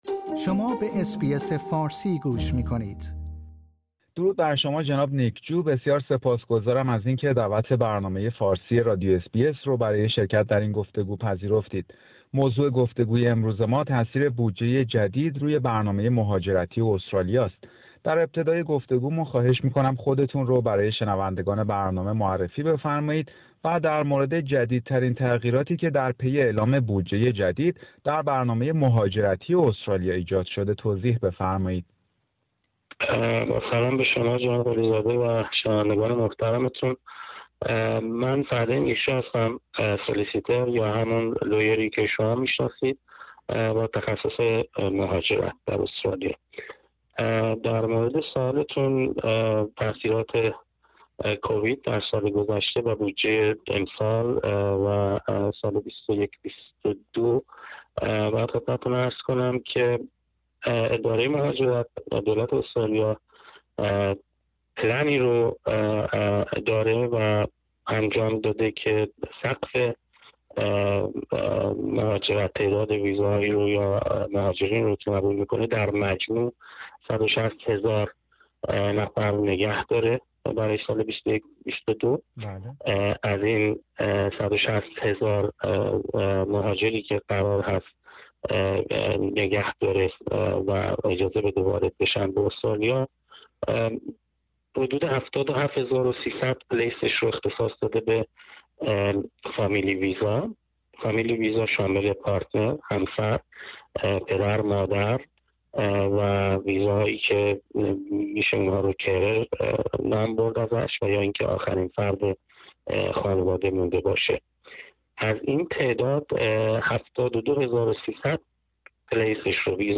گفتگویی در مورد جدیدترین تغییرات در برنامه مهاجرتی استرالیا در پی اعلام بودجه جدید